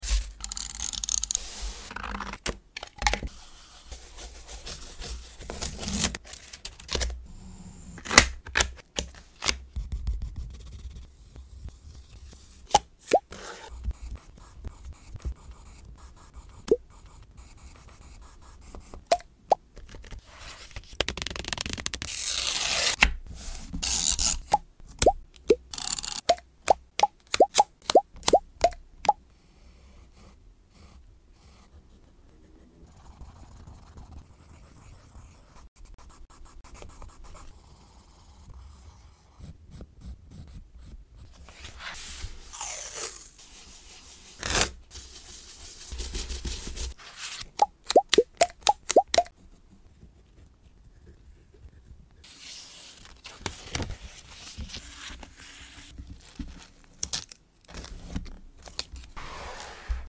ASMR August journal design/setup! 🌵🏜🎀🍒🤠 sound effects free download